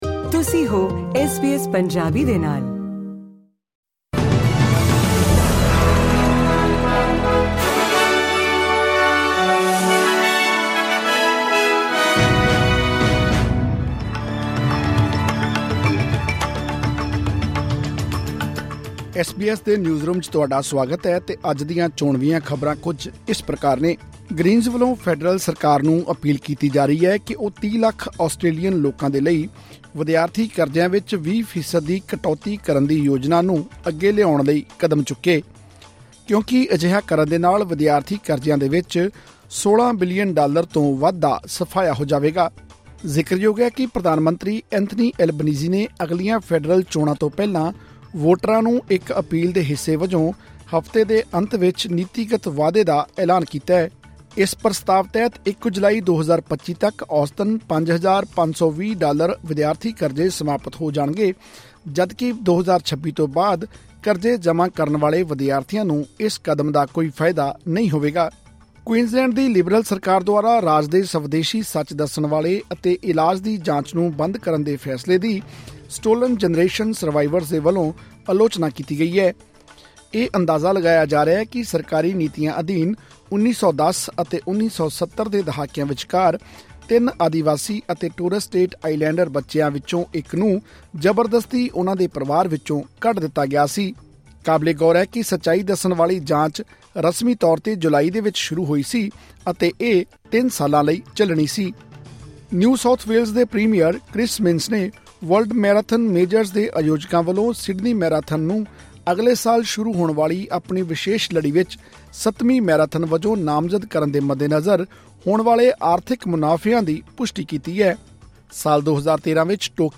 ਐਸ ਬੀ ਐਸ ਪੰਜਾਬੀ ਤੋਂ ਆਸਟ੍ਰੇਲੀਆ ਦੀਆਂ ਮੁੱਖ ਖ਼ਬਰਾਂ: 4 ਨਵੰਬਰ 2024